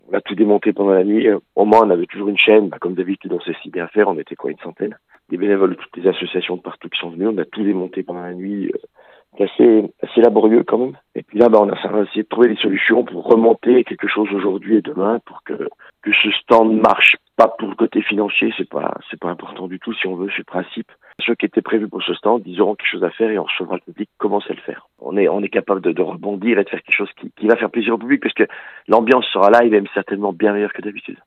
Un bâtiment des Grandes Médiévales part en fumée (Interview)
Vincent Humbert, le Président de ces Grandes Médiévales (et maire d'Andilly) revient sur cette volonté de maintenir la fête grâce à la solidarité de tous.